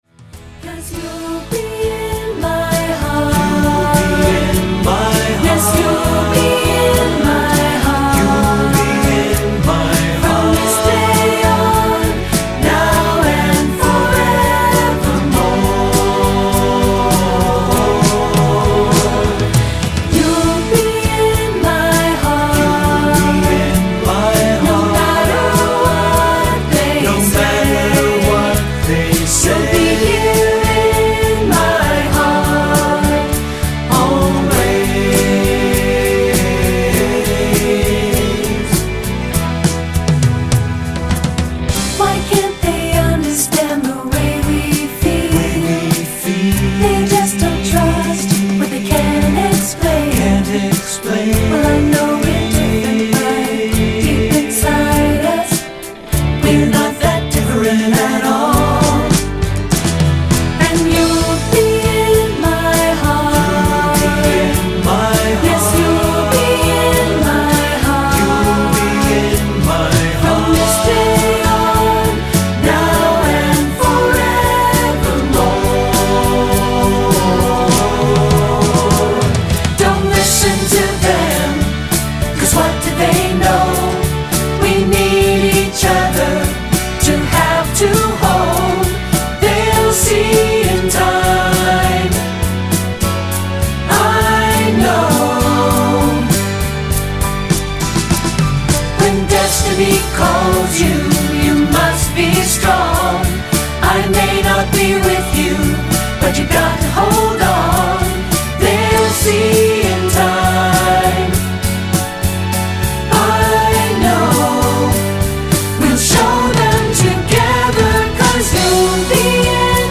Choral Movie/TV/Broadway
rock ballad